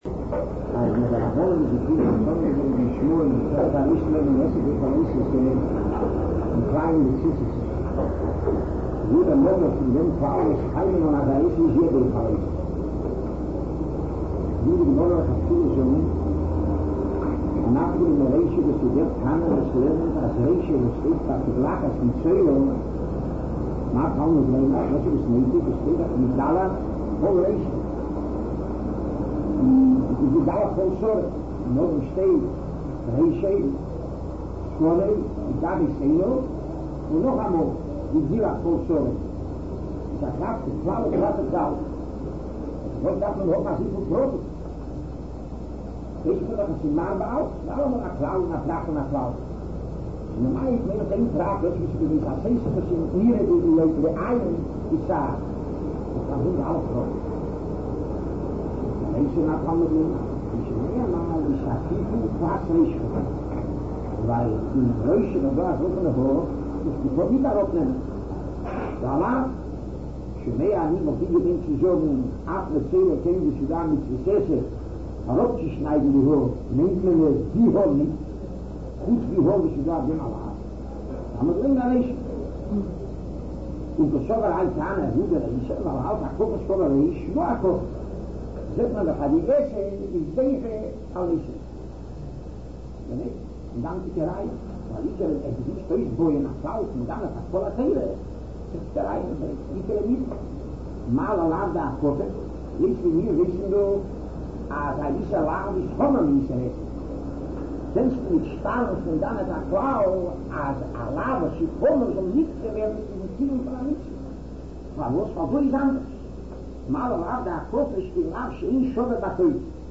Rav Gifter giving shiur on Yivamos, topic of Asei Docheh Lo Sasei part III.